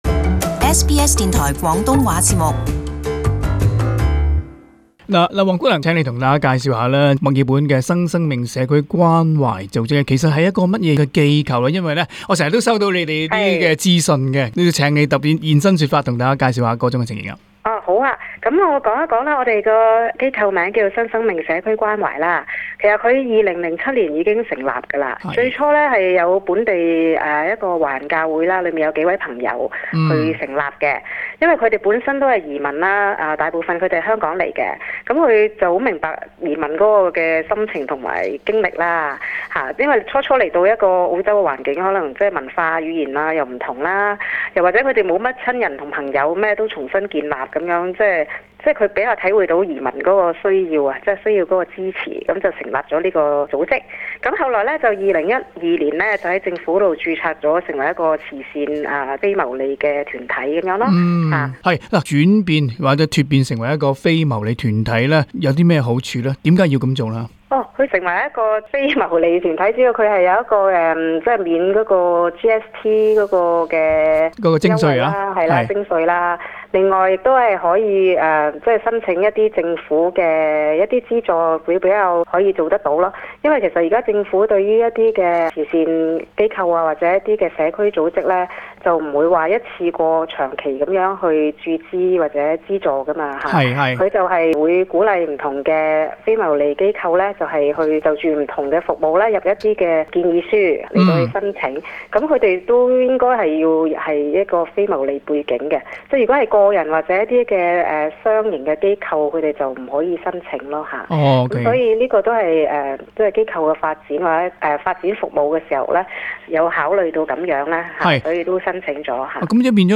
【社團專訪】維州商界促請省州政府提升Payroll Tax的徵稅門欖